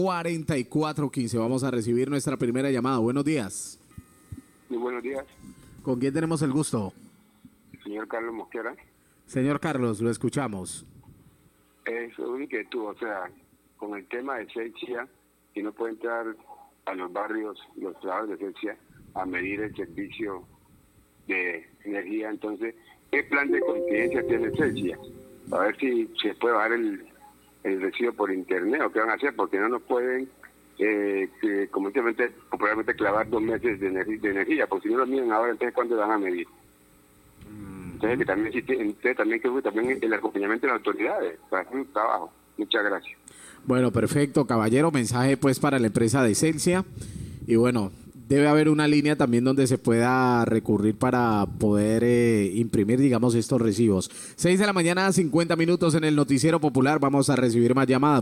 Oyente pregunta por plan de contigencia de Celsia para lectura de medidores
Radio